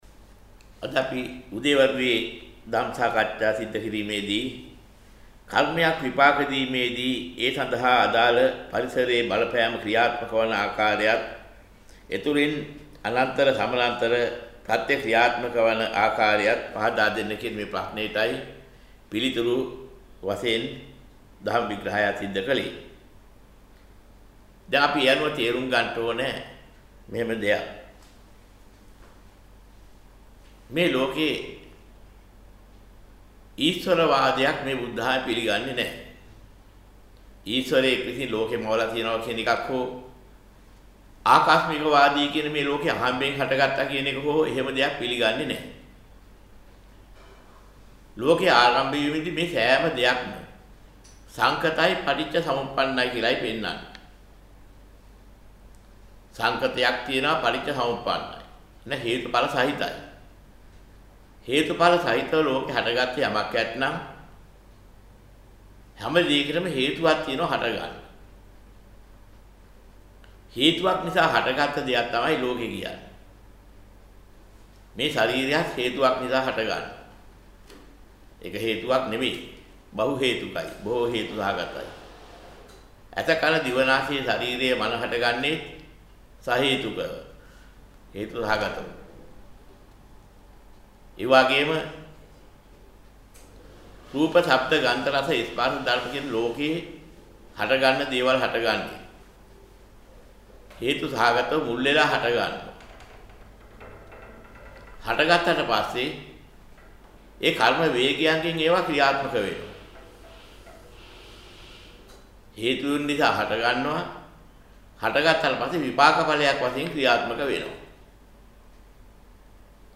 වෙනත් බ්‍රව්සරයක් භාවිතා කරන්නැයි යෝජනා කර සිටිමු 26:08 10 fast_rewind 10 fast_forward share බෙදාගන්න මෙම දේශනය පසුව සවන් දීමට අවැසි නම් මෙතැනින් බාගත කරන්න  (24 MB)